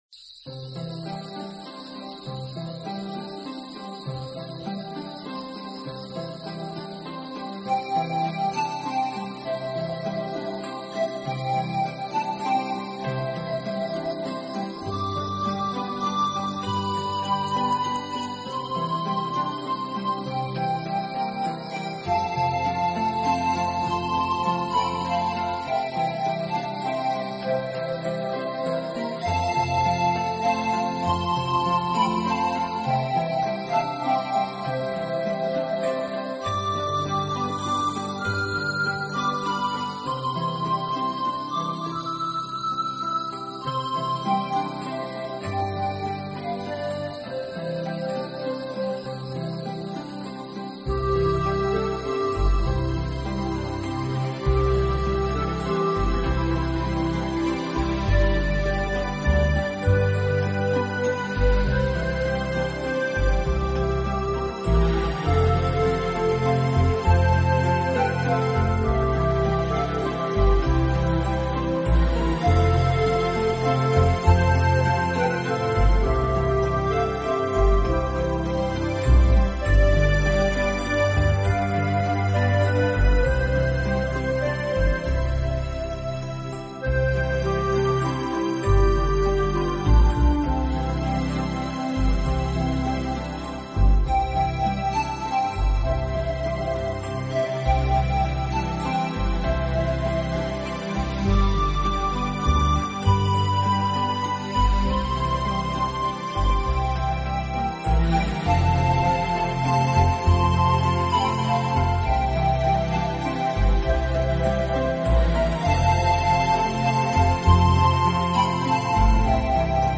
新世纪纯音乐
专辑语言：纯音乐